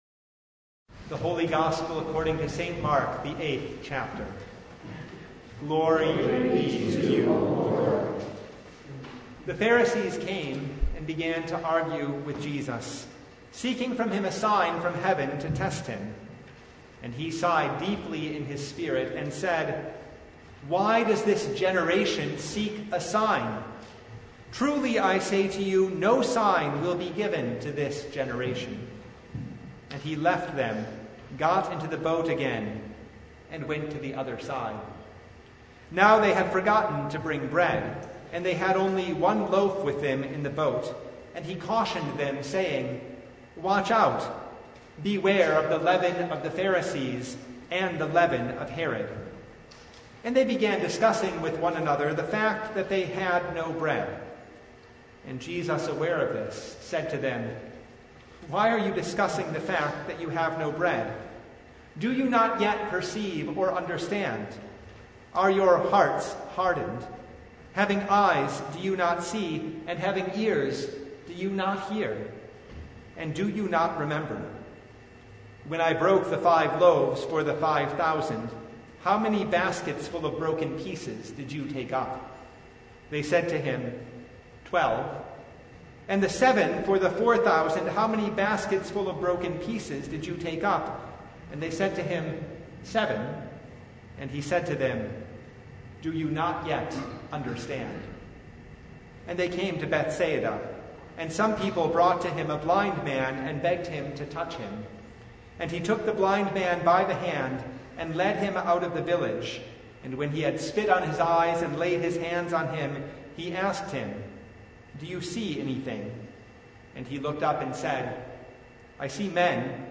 Passage: Mark 8:11-26 Service Type: Lent Midweek Noon Topics: Sermon Only